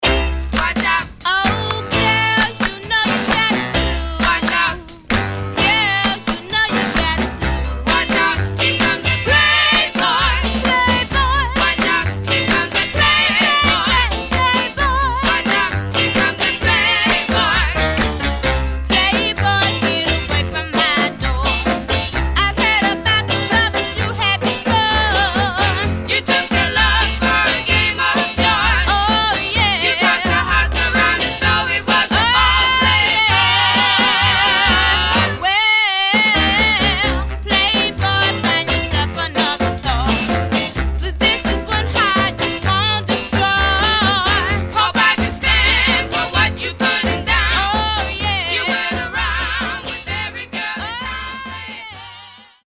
Surface noise/wear
Mono
Black Female Group